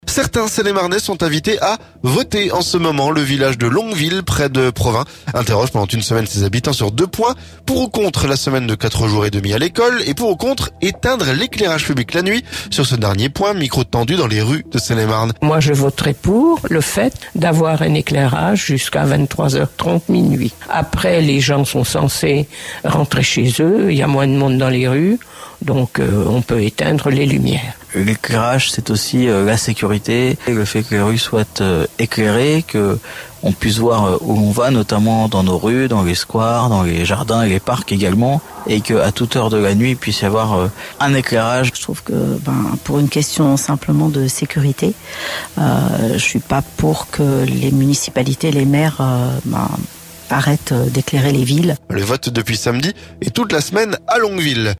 Pour ou contre éteindre l'éclairage public la nuit ? Sur ce dernier point, micro tendu dans le rues de Seine-et-Marne.